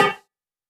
035 HiBongo LoFi.wav